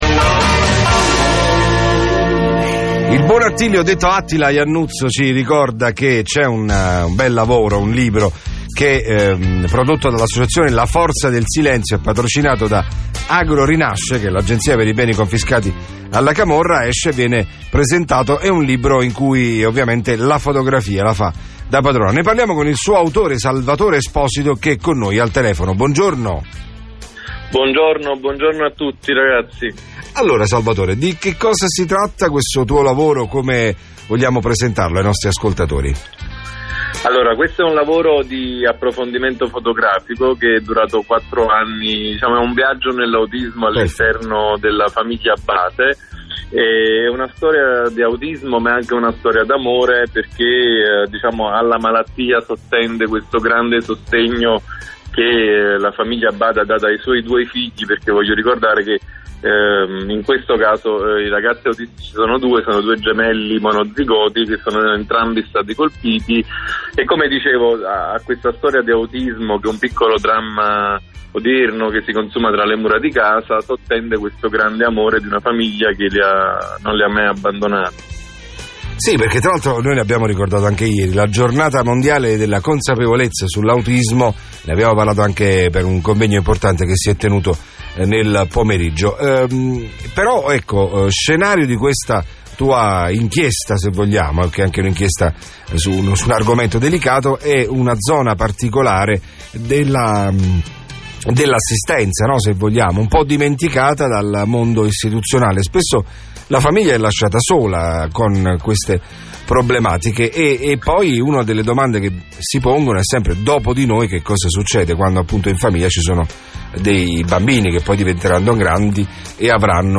interviewed on Radio CRC.